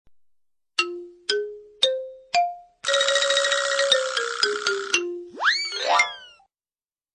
SFX鬼鬼祟祟的声音音效下载
SFX音效